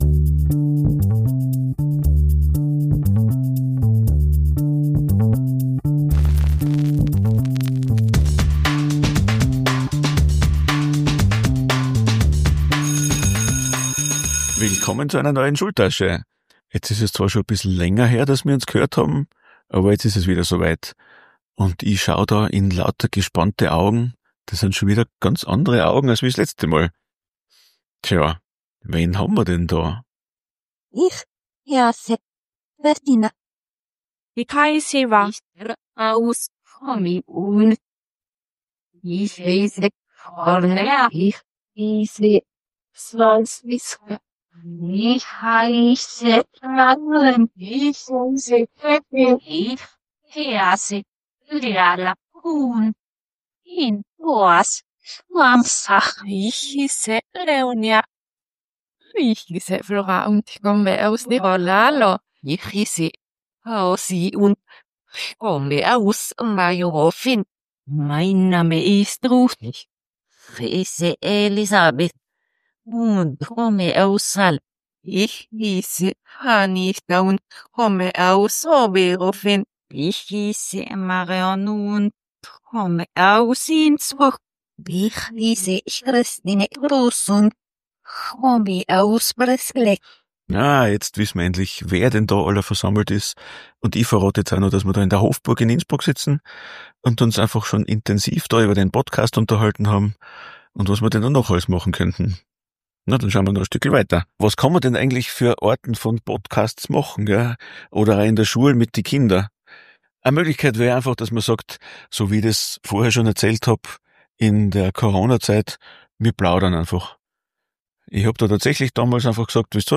Aufgenommen während und nach der Fortbildung in der Hofburg